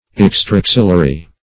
Search Result for " extraaxillary" : The Collaborative International Dictionary of English v.0.48: Extraaxillar \Ex`tra*ax"il*lar\, Extraaxillary \Ex`tra*ax"il*la*ry\a. (Bot.) Growing outside of the axils; as, an extra-axillary bud.